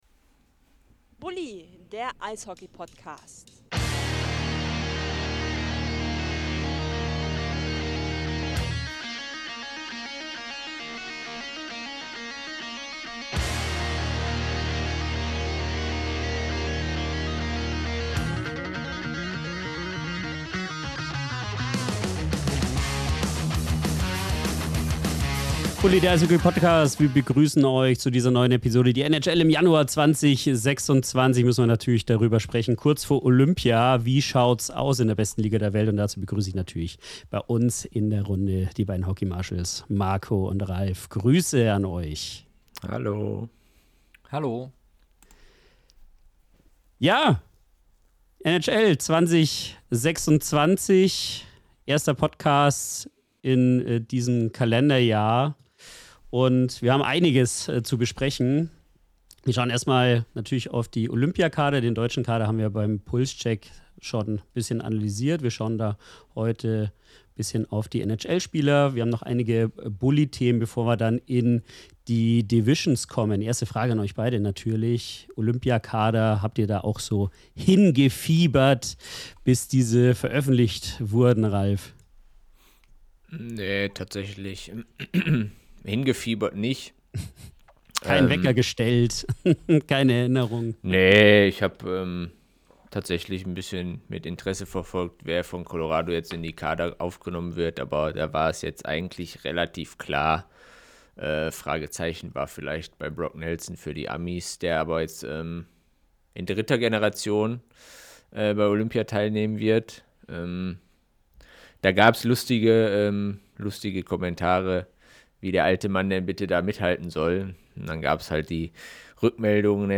Gleichzeitig nehmen wir euch mit in emotionale Debatten und liefern Einschätzungen, die über reine Tabellenstände hinausgehen.